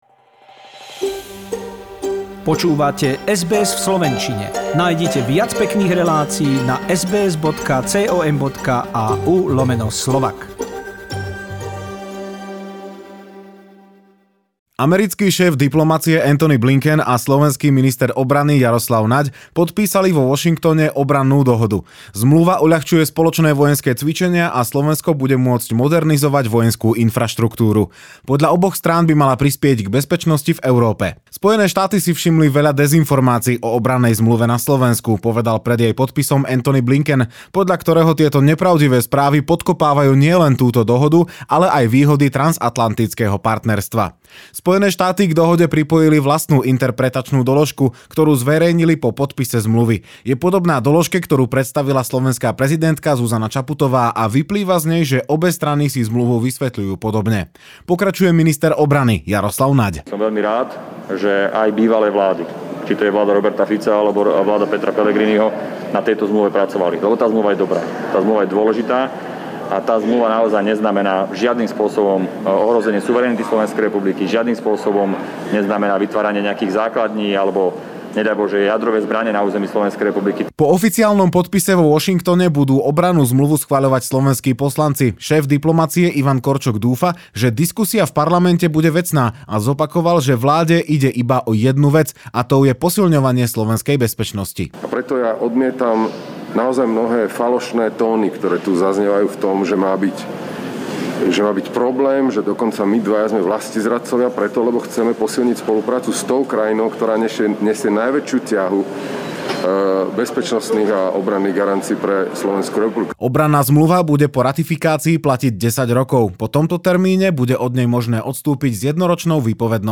Súhrn správ zo Slovenska k 6.2.2022